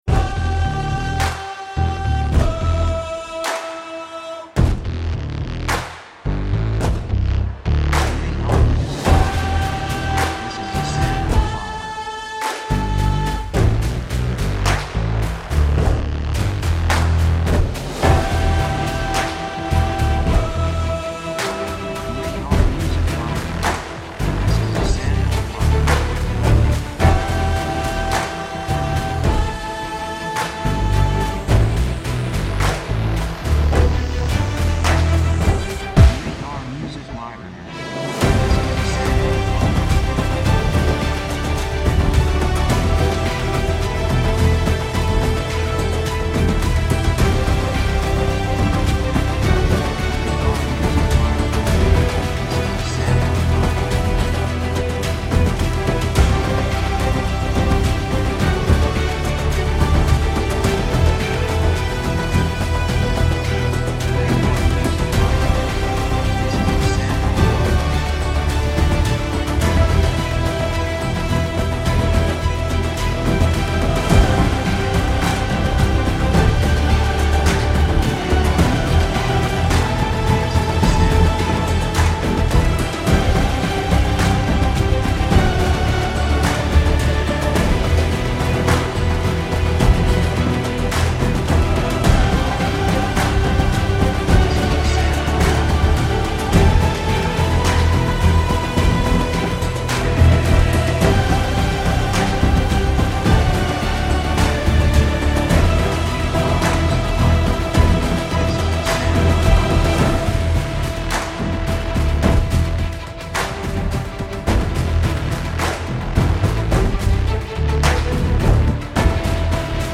雰囲気激しい, 壮大, 決意, 喜び
曲調ニュートラル
楽器オーケストラ, パーカッション, ボーカル, 手拍子
サブジャンルシネマティック, オーケストラハイブリッド
テンポやや速い